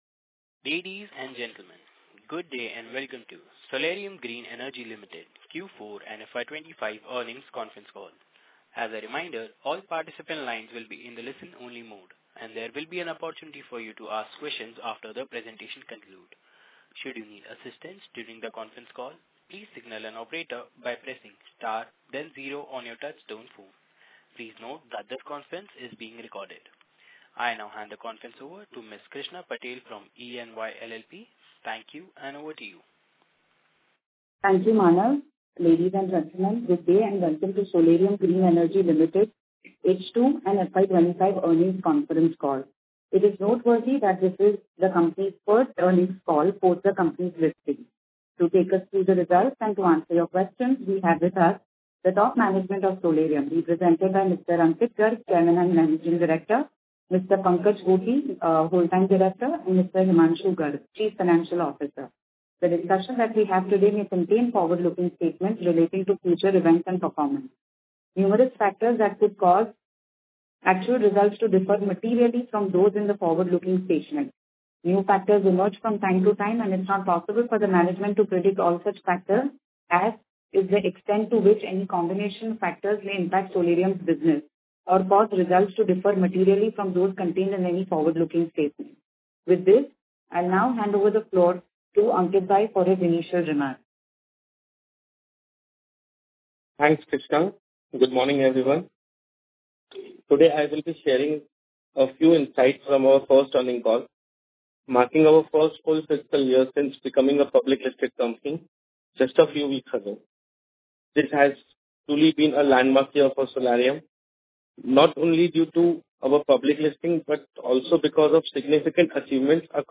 Solarium-H2-FY25-Earnings-call-audio.mp3